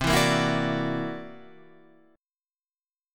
C 9th Flat 5th